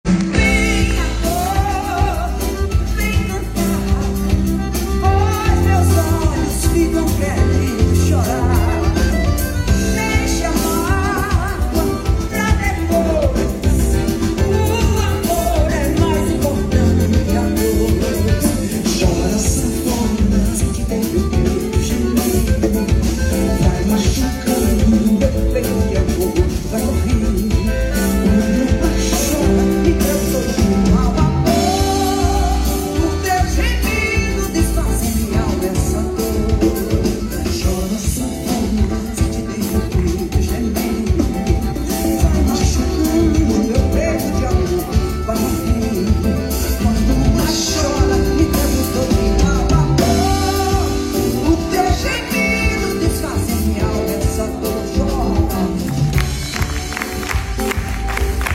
🌟 💃 Forró, hits e muita energia!